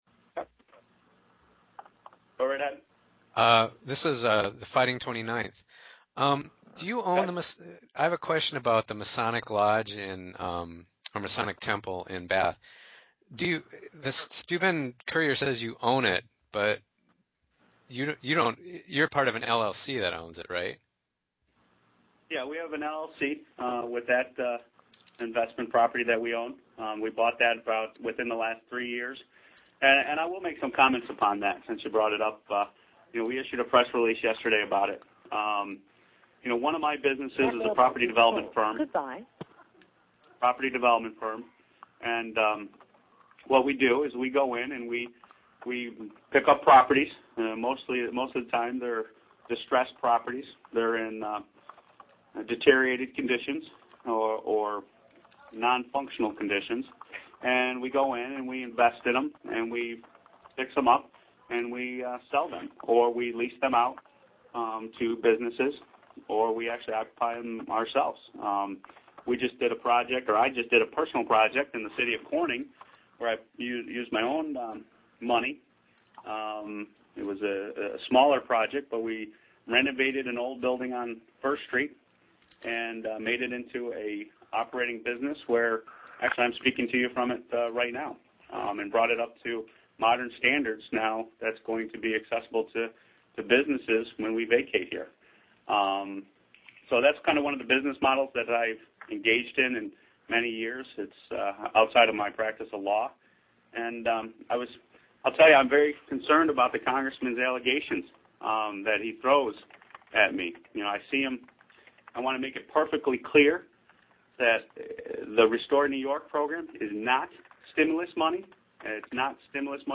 Here's 20 minutes of press conference for the real political junkies.